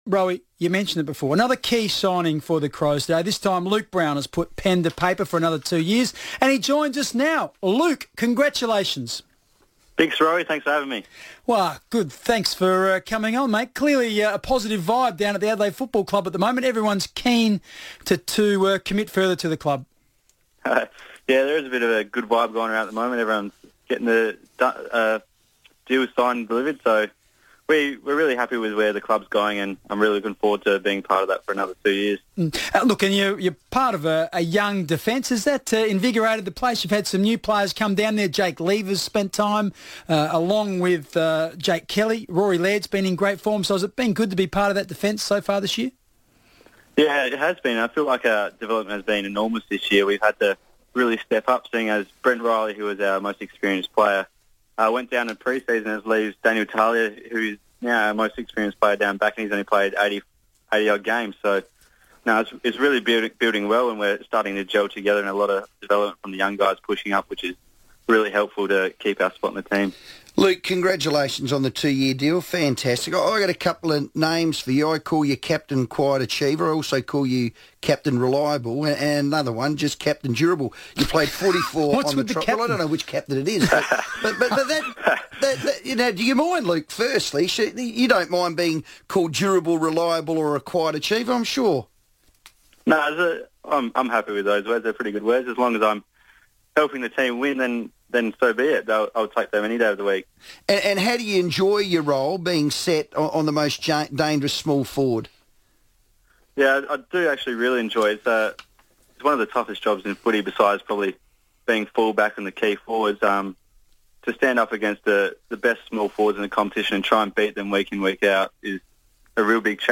Defender Luke Brown spoke on the FIVEaa Sports Show after re-signing with the Crows for a further two years